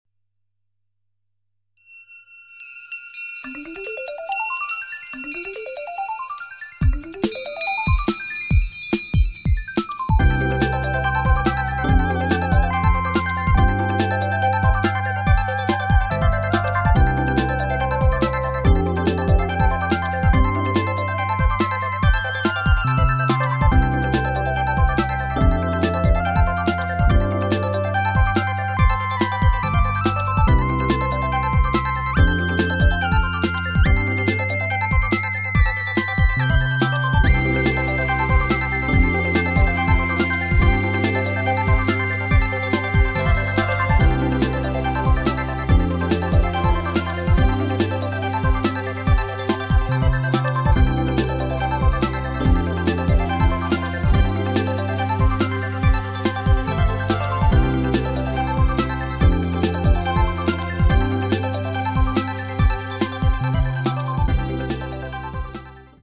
サブウーファ部のみ、12db音圧を上げてみました。
・・・いわゆる「ブーミーな低音」が再現されました？。